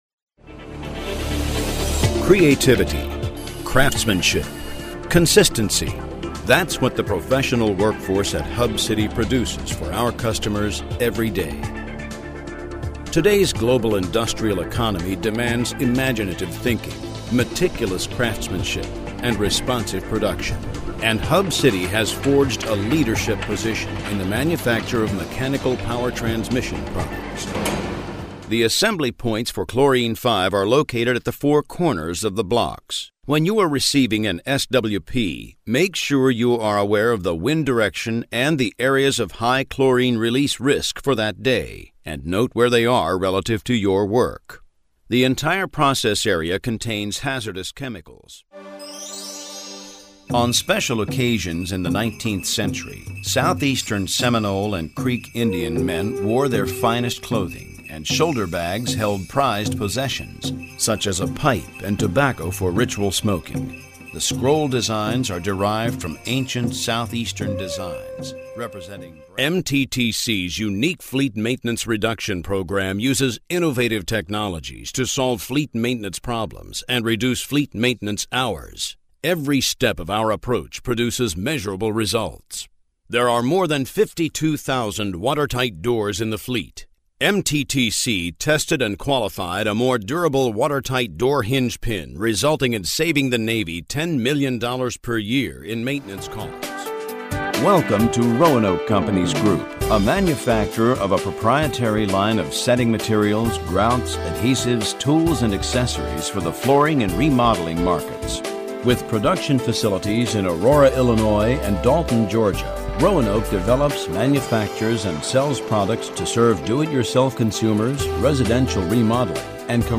Warm, Friendly, Storyteller
Narration